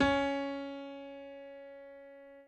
admin-fishpot/b_pianochord_v100l1-2o5cp.ogg at main